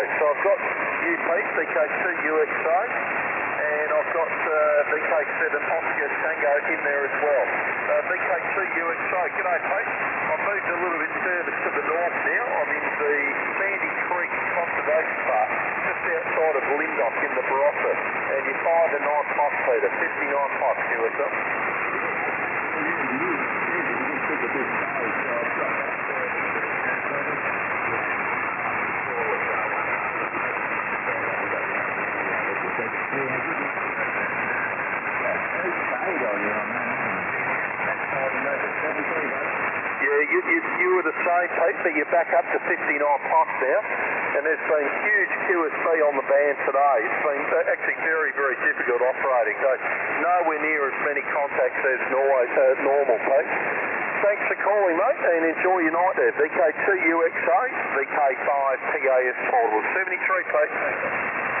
I ran the Yaesu FT-857d and the 20/40/80m linked dipole for this activation.
short piece of audio of me operating